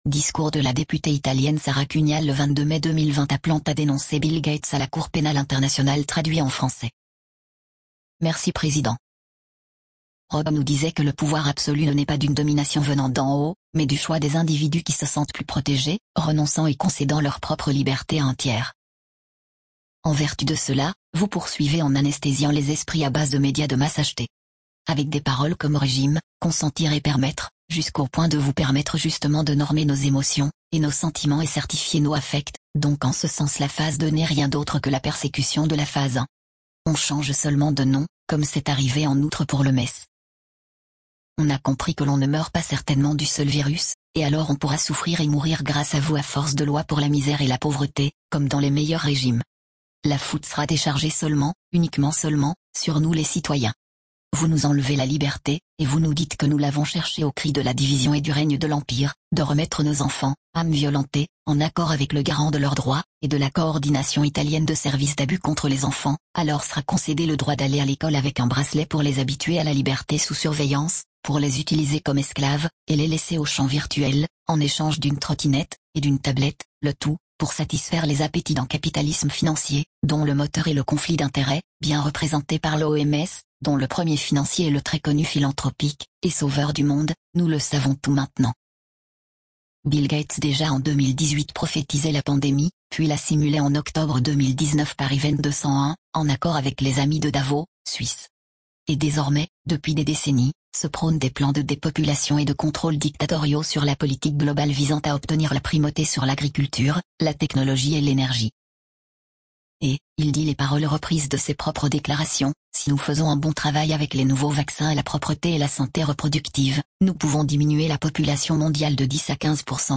Discours de la députée italienne Sara CUNIAL du 22 Mai 2020:Bill Gates à la CPI
discours de Sara Cunial du 22 Mai 2020.mp3